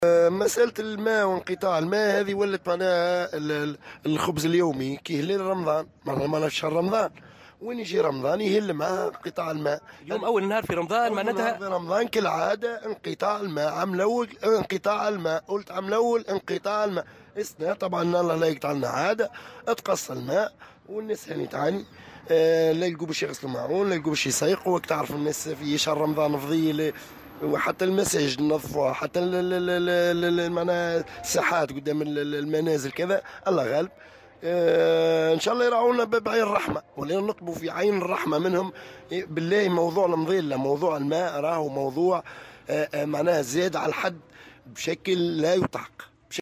أحد المحتجين